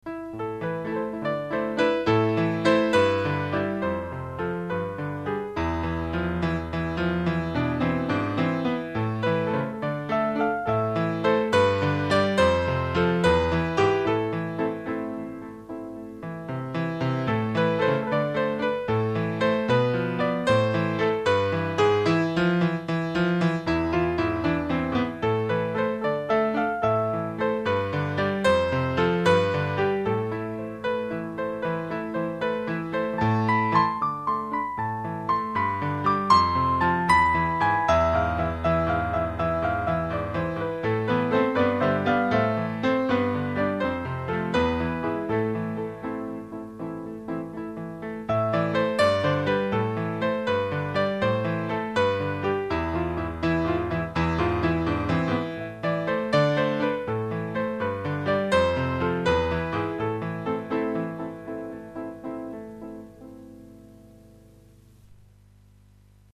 brano strumentale inedito, per pianoforte